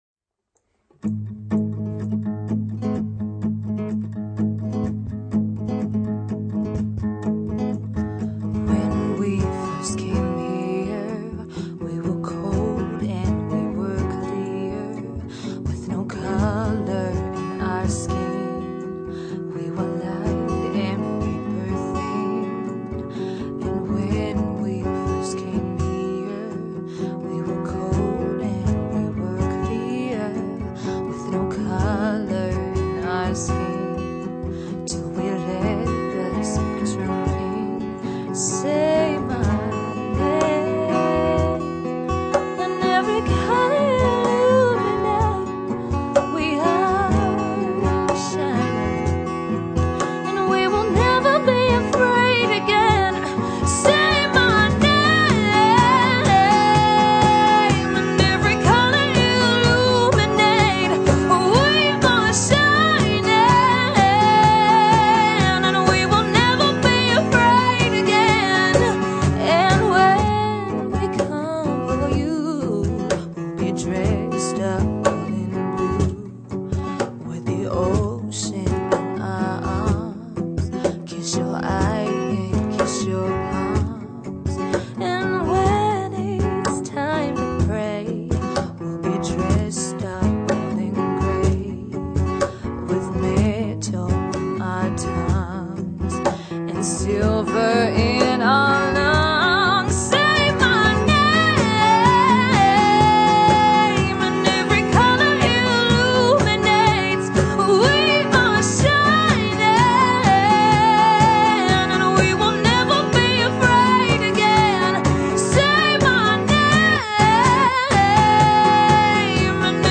Fantastic Talented Singer & Guitarist
Her live acoustic show provides a mix of pop, soul and RnB